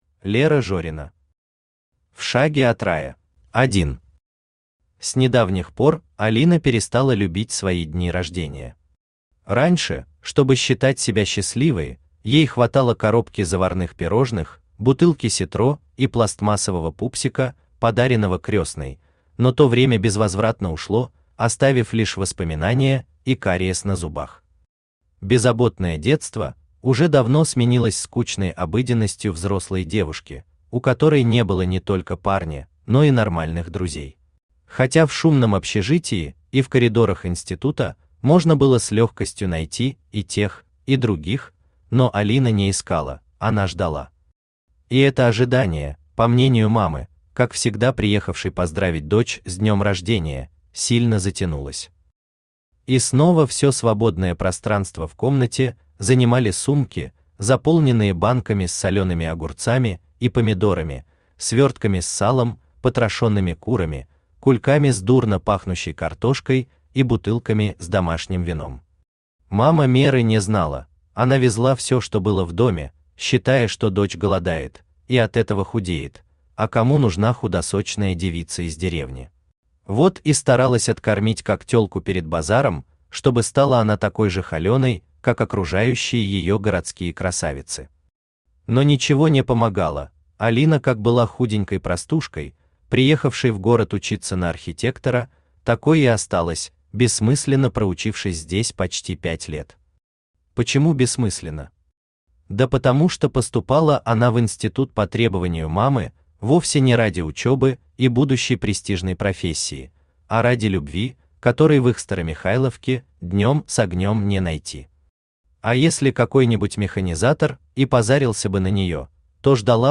Аудиокнига В шаге от рая | Библиотека аудиокниг
Aудиокнига В шаге от рая Автор Лера Жорина Читает аудиокнигу Авточтец ЛитРес.